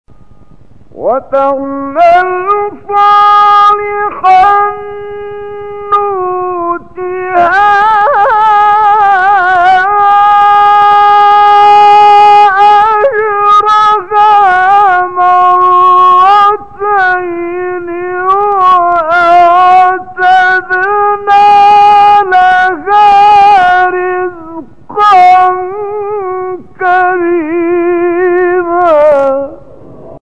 گروه شبکه اجتماعی: فرازهای صوتی اجرا شده در مقام حجاز با صوت کامل یوسف البهتیمی ارائه می‌شود.
برچسب ها: خبرگزاری قرآن ، ایکنا ، شبکه اجتماعی ، مقاطع صوتی ، مقام حجاز ، کامل یوسف البهتیمی ، قاری مصری ، تلاوت قرآن ، قرآن ، iqna